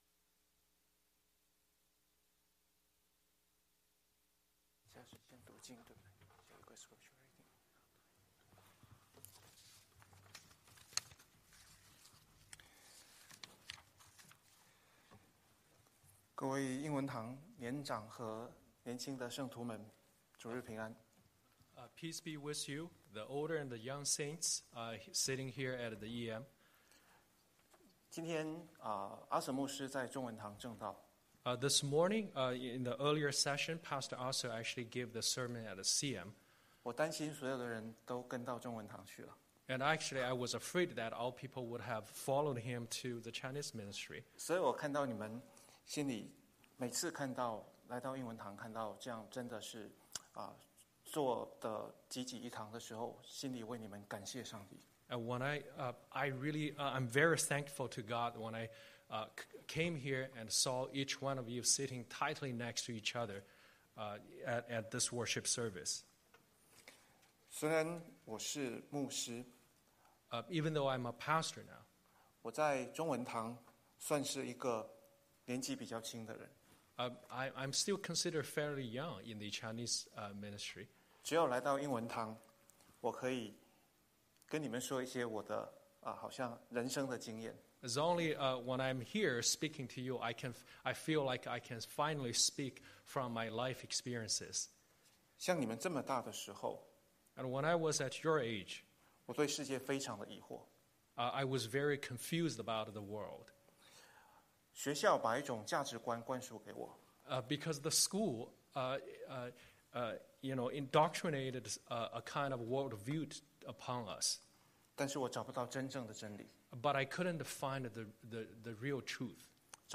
Series: Sunday Sermon